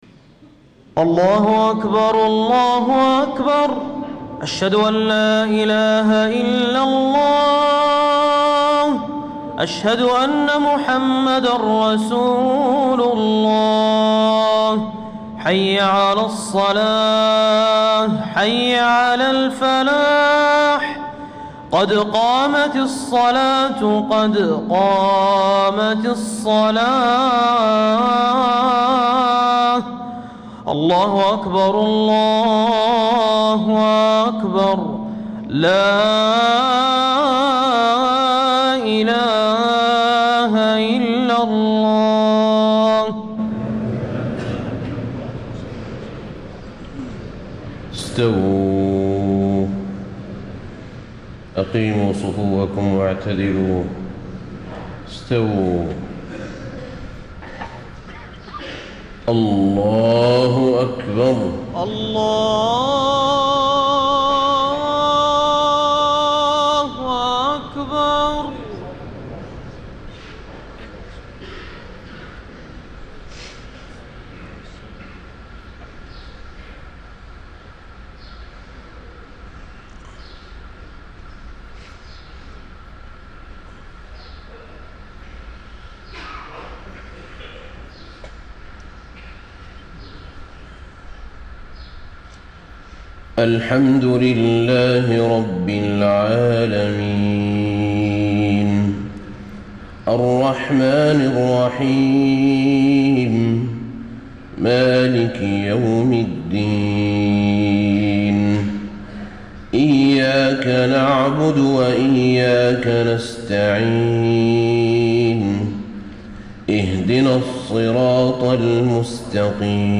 صلاة الفجر 2-6-1435 ما تيسر من سورة الكهف > 1435 🕌 > الفروض - تلاوات الحرمين